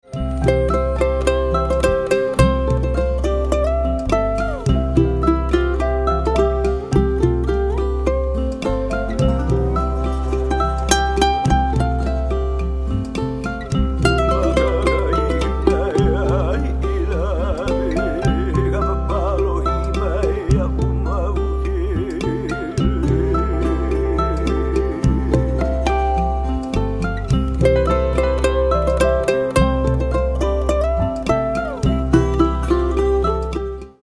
• Genre: 'Ukulele instrumentals.
performing chant